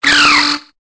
Cri de Tritonde dans Pokémon Épée et Bouclier.